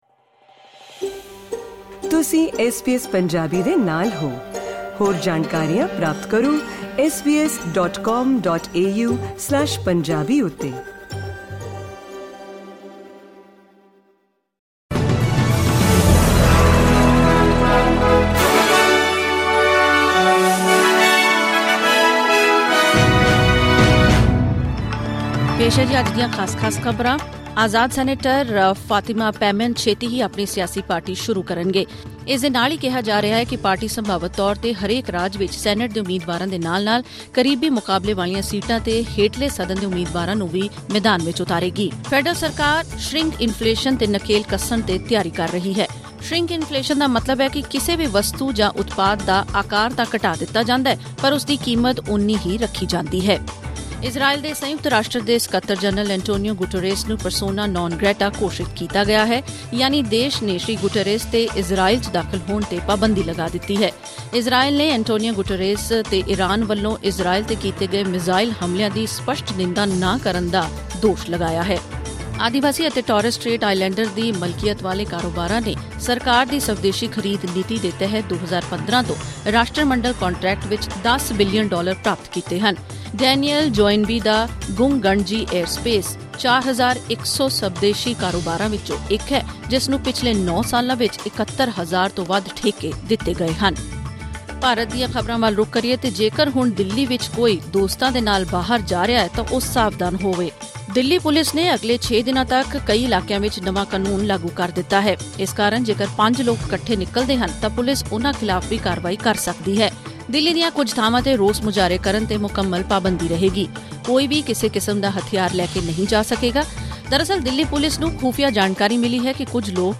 ਐਸ ਬੀ ਐਸ ਪੰਜਾਬੀ ਤੋਂ ਆਸਟ੍ਰੇਲੀਆ ਦੀਆਂ ਮੁੱਖ ਖ਼ਬਰਾਂ: 3 ਅਕਤੂਬਰ 2024